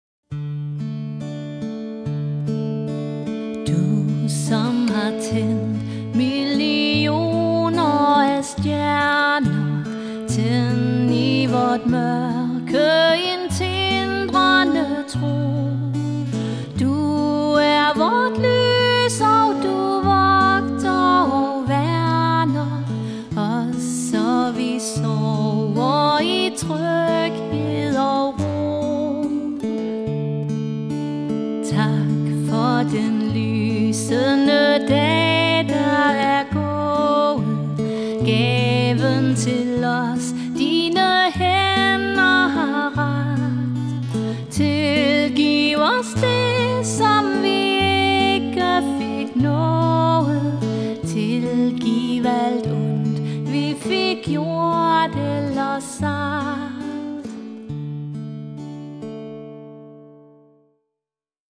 Sangerinde og solomusiker (sang og guitar)
Smukt, akustisk og rørende.
Jeg spiller guitar til og medbringer selv udstyr.